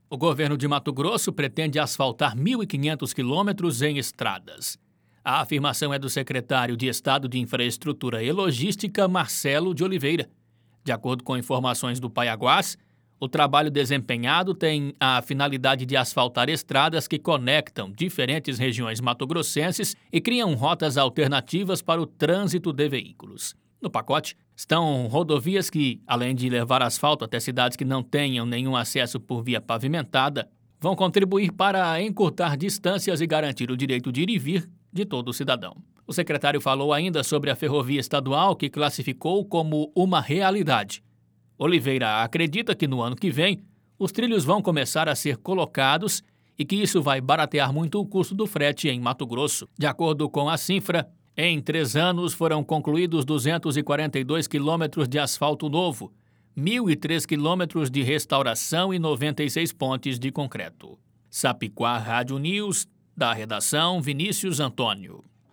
Boletins de MT 09 mar, 2022